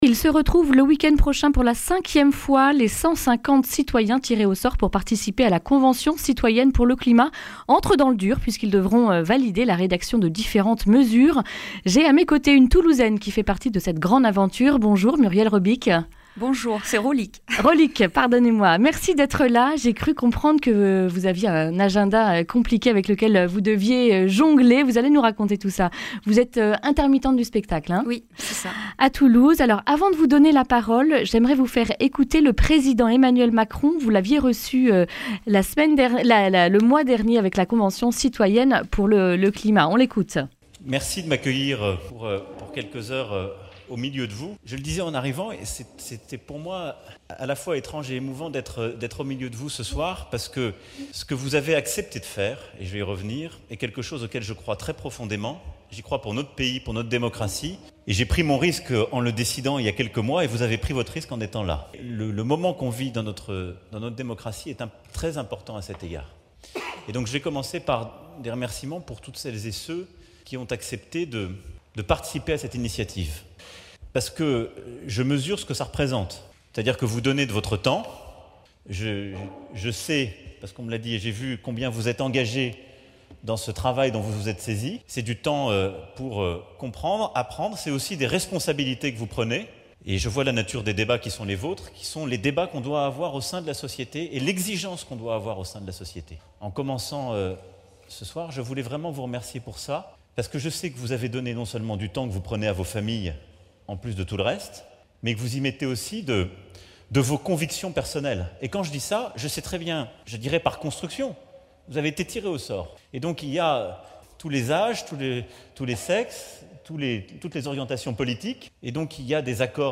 Une Toulousaine tirée au sort raconte la Convention Citoyenne pour le Climat